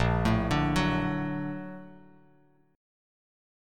A#+M7 chord